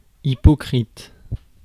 Ääntäminen
IPA: /i.pɔ.kʁit/